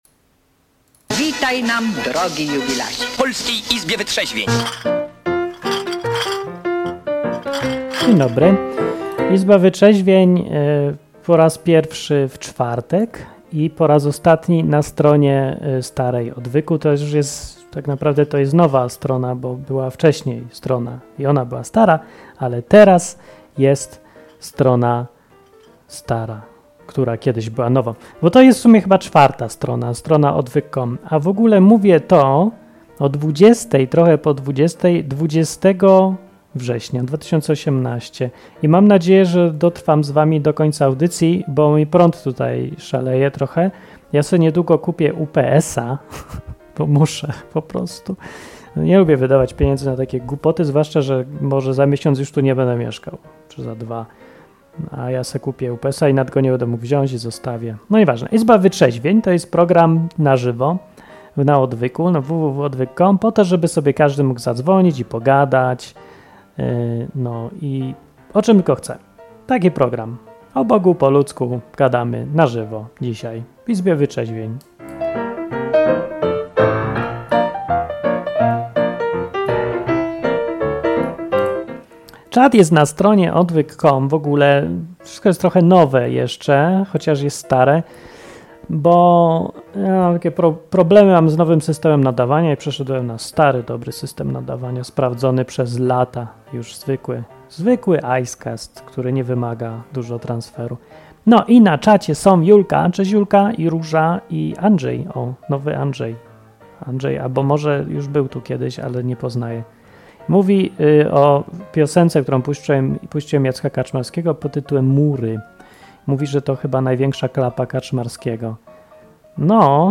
Z telefonów od słuchaczy dowiesz się czego dziś się uczy na lekcjach religii w szkole. Był też inny temat: role mężczyzn i kobiet się zamieniają miejscami.